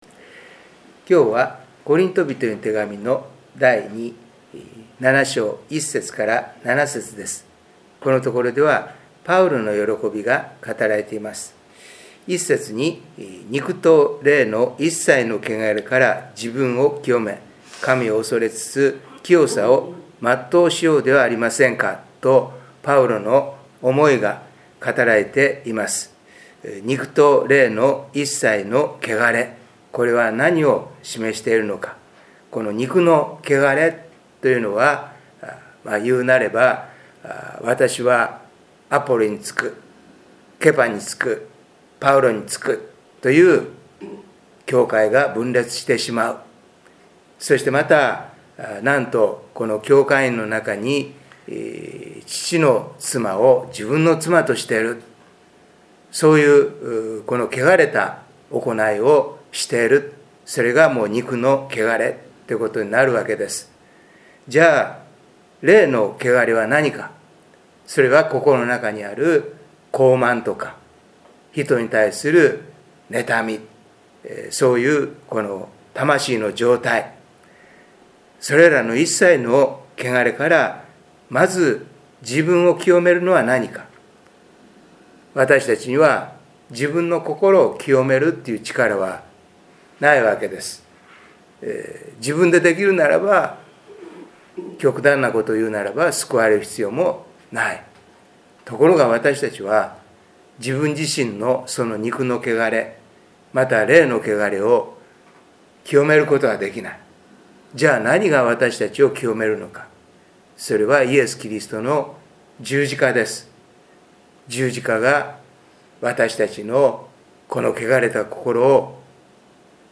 礼拝メッセージ
オーディオ礼拝メッセージです。